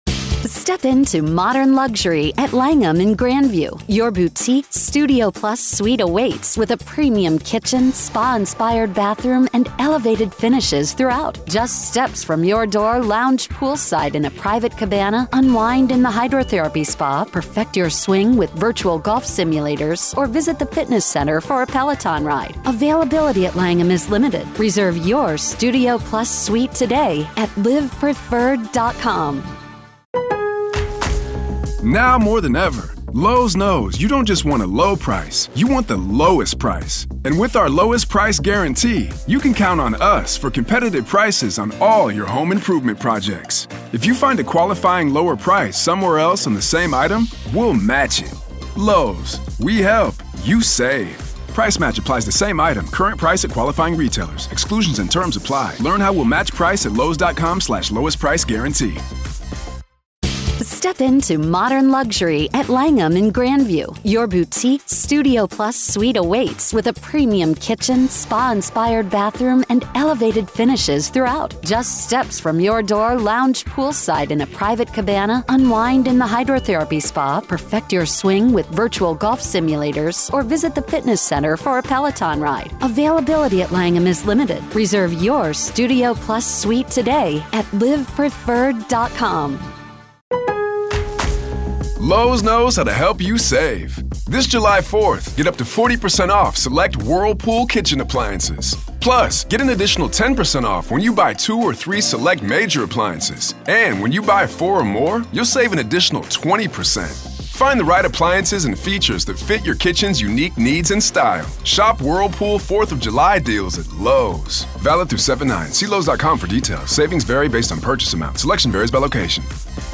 Dr. Katherine Ramsland Interview Behind The Mind Of BTK Part 4